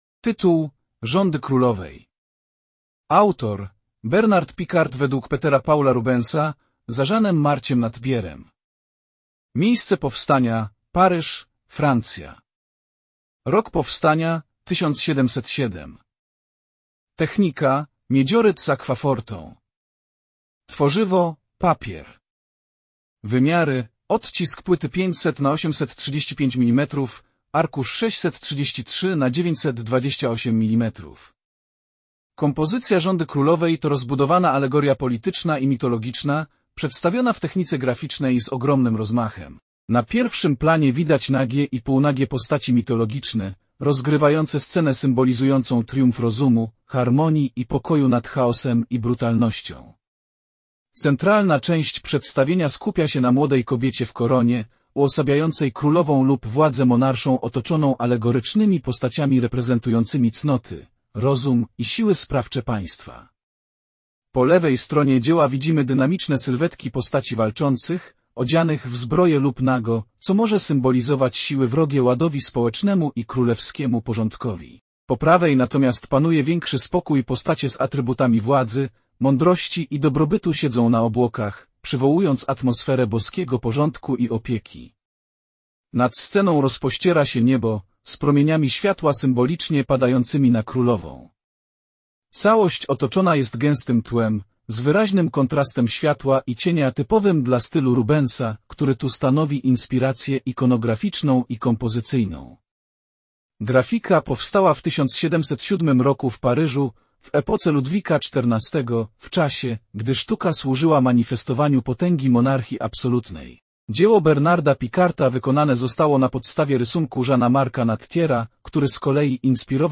MNWr_audiodeskt_Rzady_krolowej.mp3